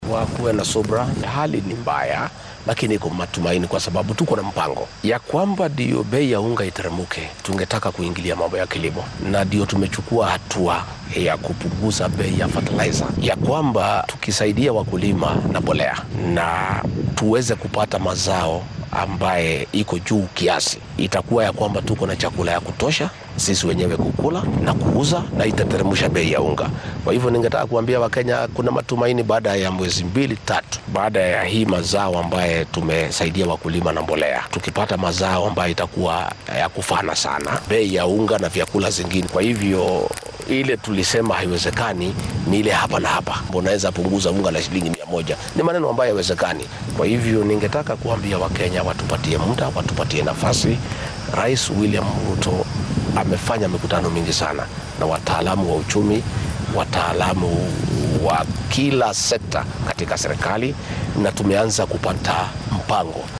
Ku xigeenka madaxweynaha dalka Rigathi Gachagua oo talefishinka maxalliga ee Citizen wareysi gaar ah ku siiyay ismaamulka Nyeri ayaa shacabka Kenyaanka ah ka codsaday inay maamulka cusub ee talada dalka la wareegay ay ka war sugaan labo ilaa saddex bilood si looga gudbo xaaladda cakiran.